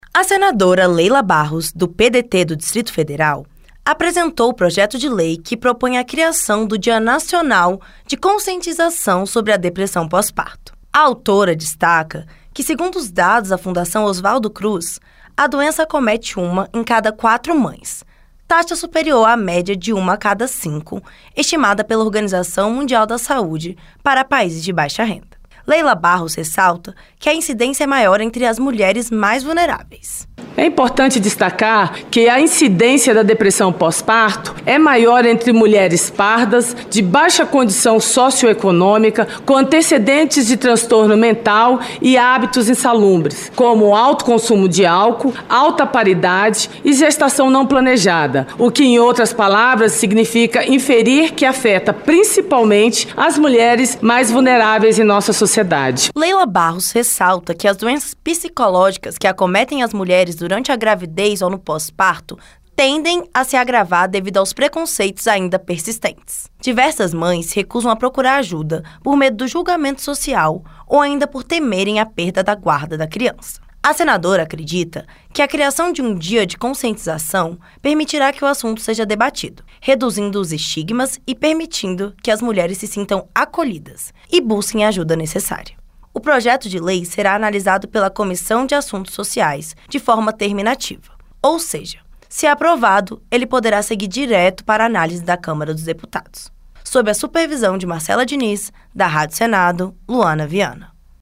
Senadora Leila Barros